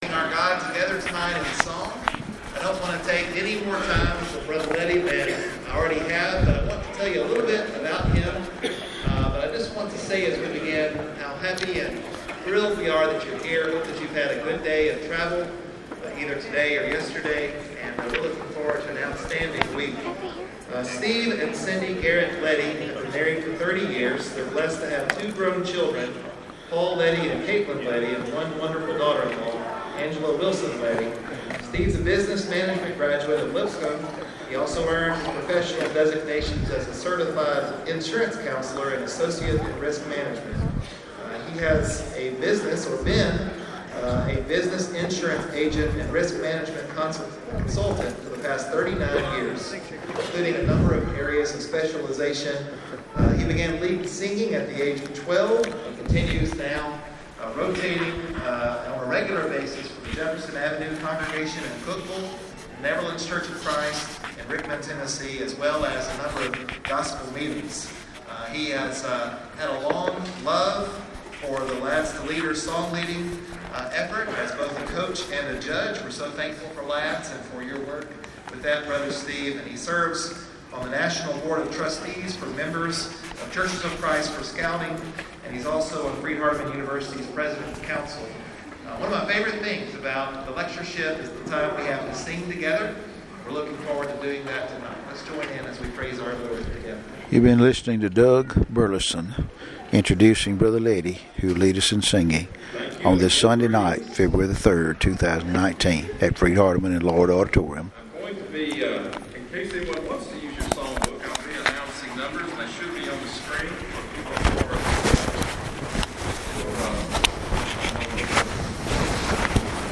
February 3 2019 Big Singing pm